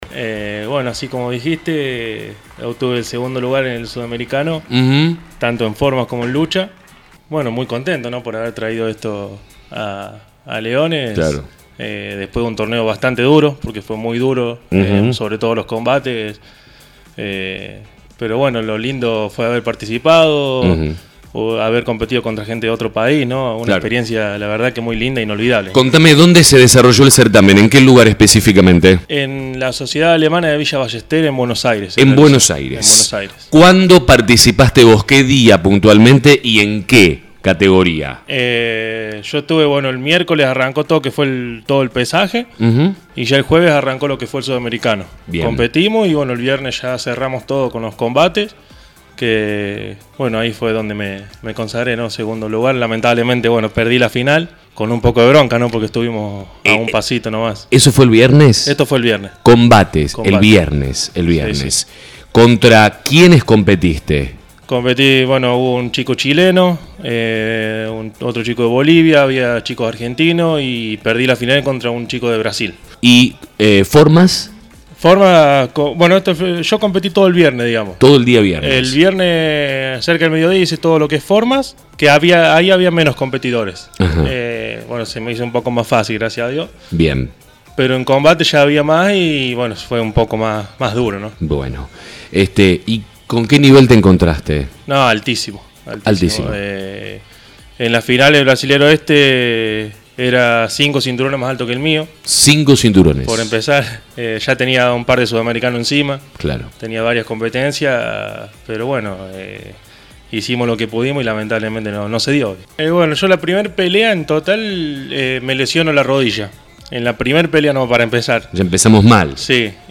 Escuchá un extracto del diálogo: